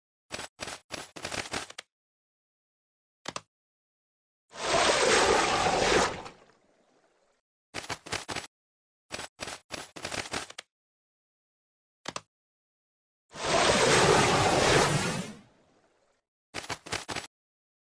1. Звук сотворения Акумы (2 версии) n2. Как звучит создание Акумы (2 варианта)